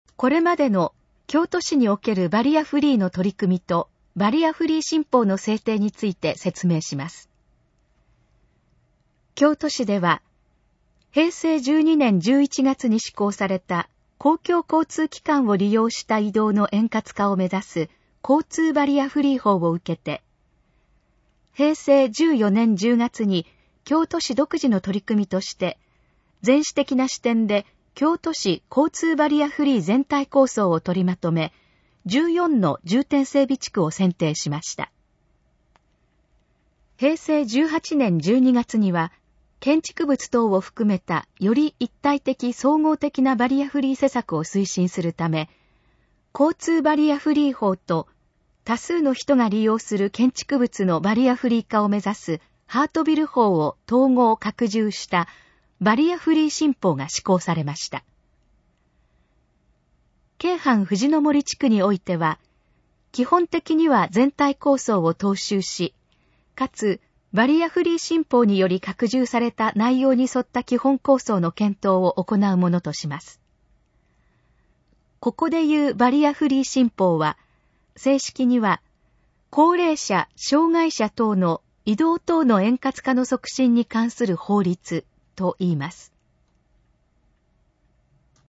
このページの要約を音声で読み上げます。
ナレーション再生 約346KB